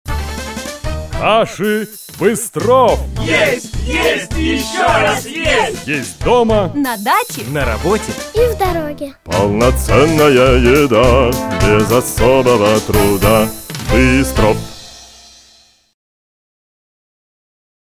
Вид рекламы: Радиореклама